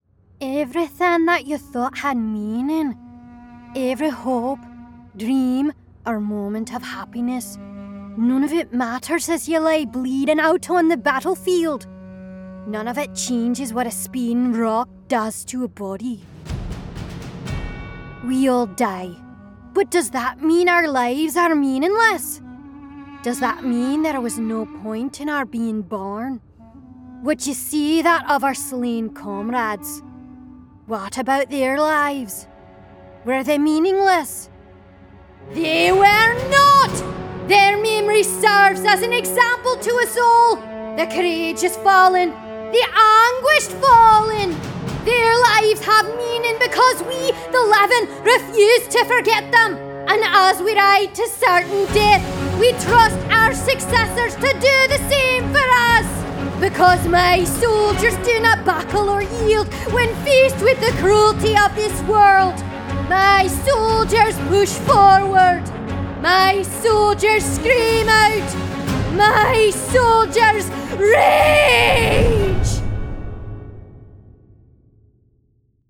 Inglés (Estados Unidos)
P.D. No te preocupes, puedo ser normal si me lo pides